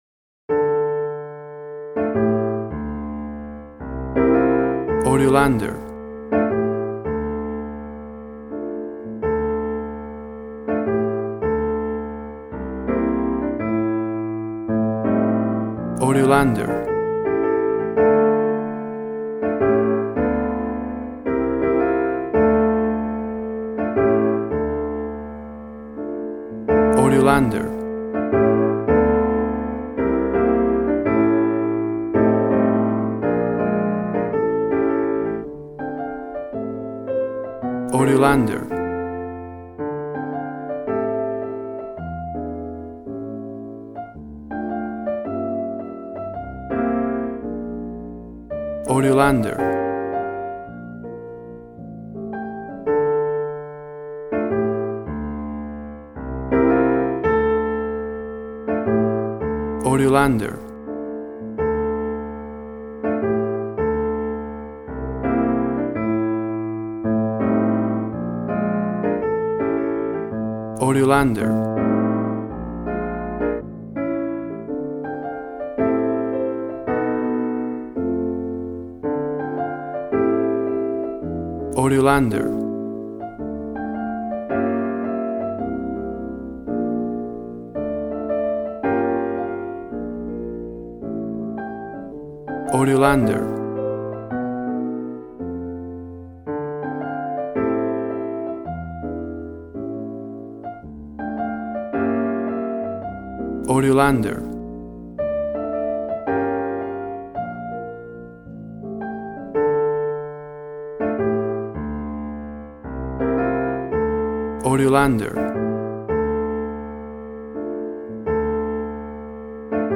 Traditional jazz piano.
Tempo (BPM): 110